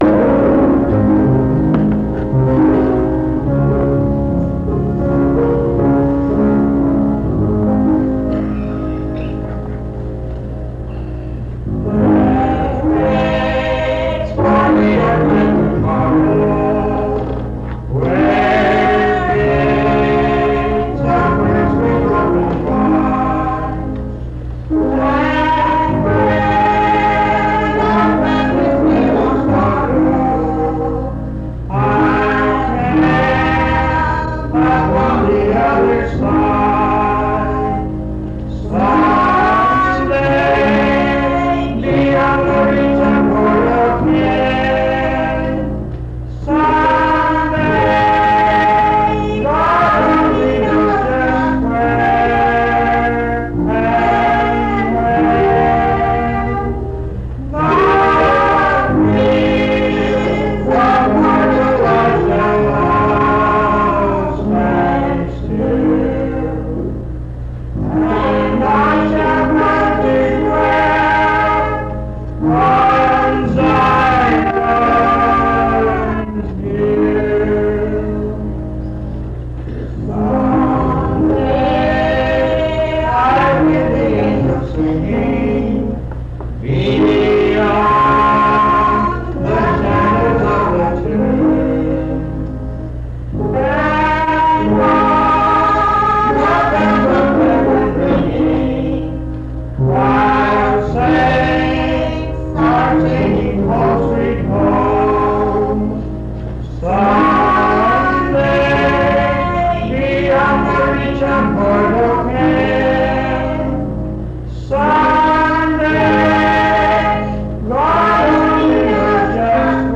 This recording is from the Monongalia Tri-District Sing. Highland Park Methodist Church, Morgantown, Monongalia County, WV.